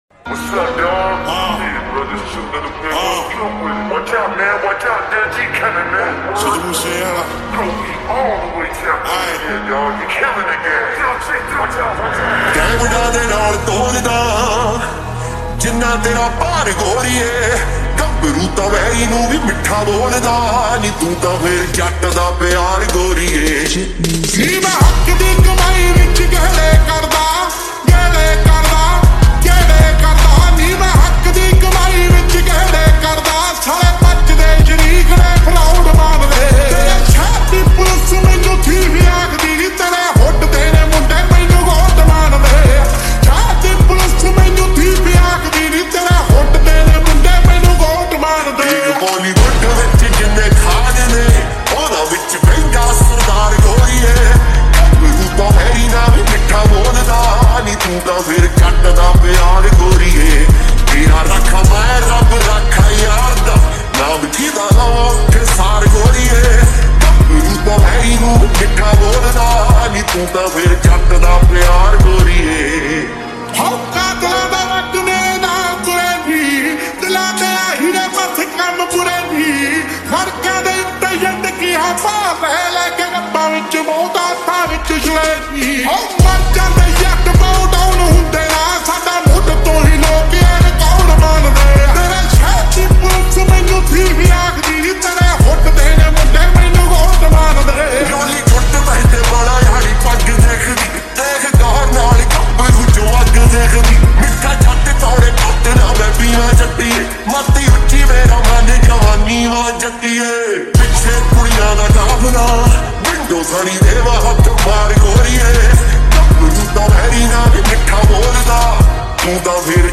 SLOWED REVERB